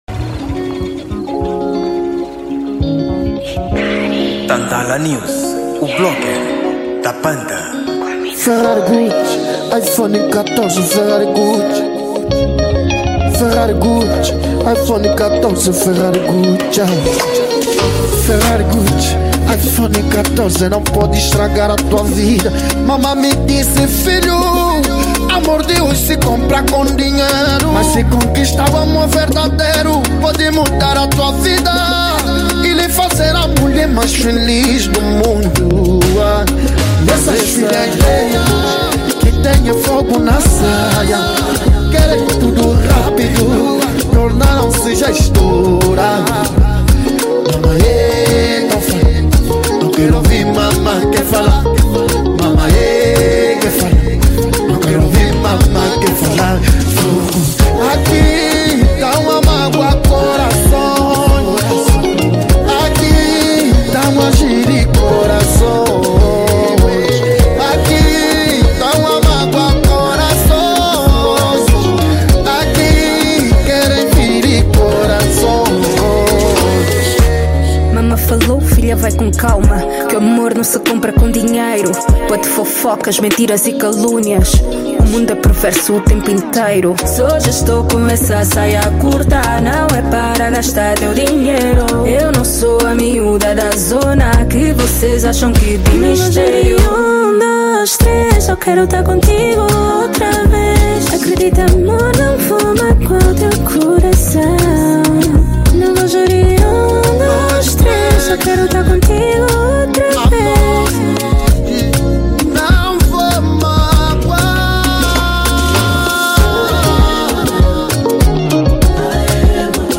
Gênero: Afro Pop